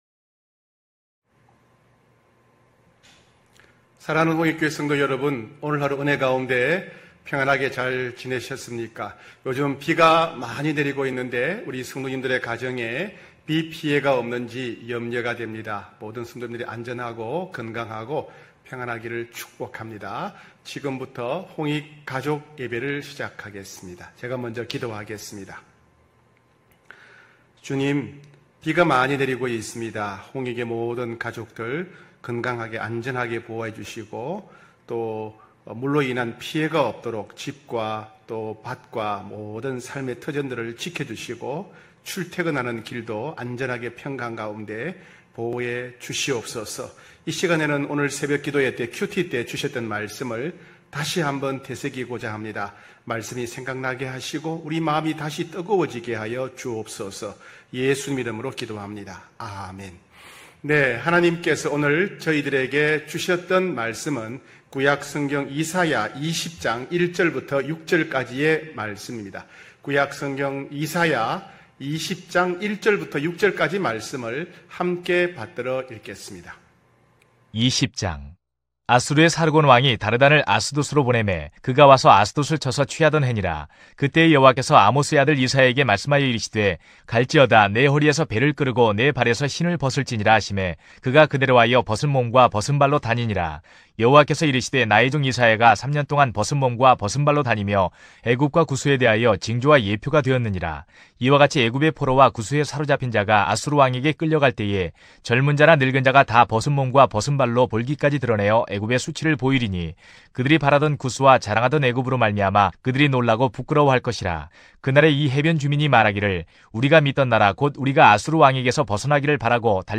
9시홍익가족예배(8월6일).mp3